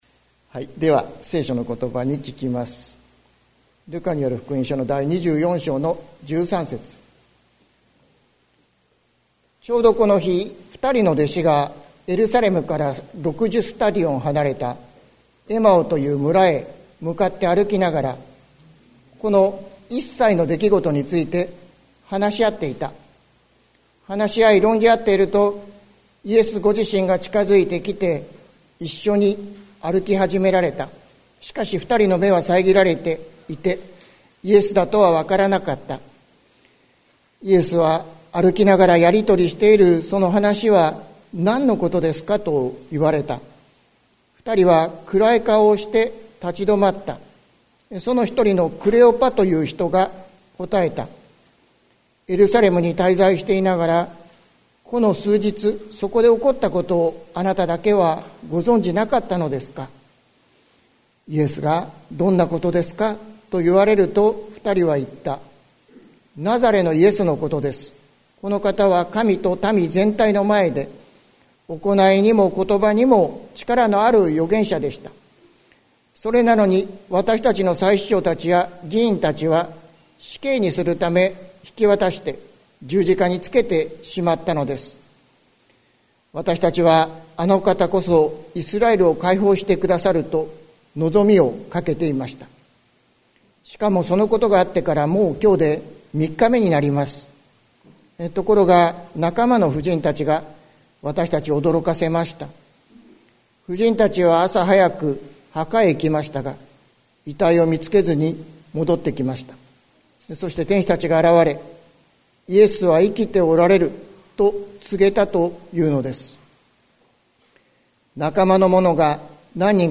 2023年04月09日朝の礼拝「夕暮れになお光あり」関キリスト教会
説教アーカイブ。